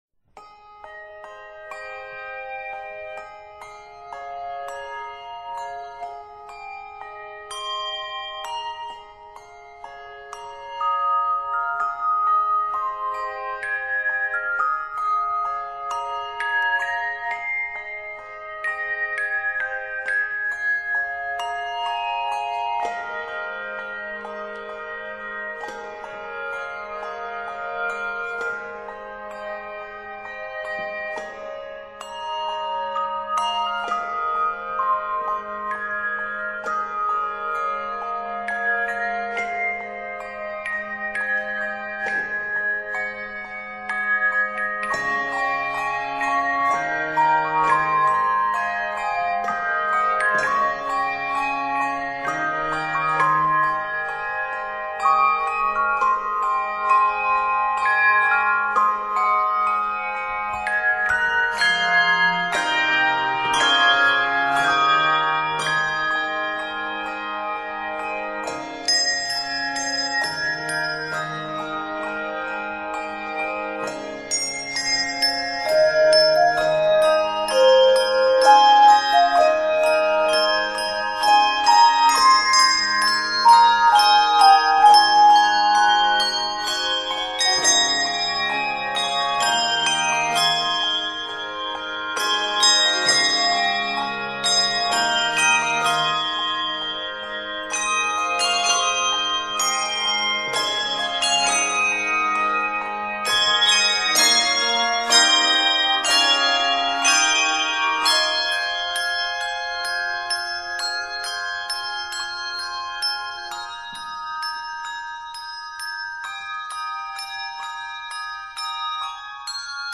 is scored in Ab Major, f minor, and C Major.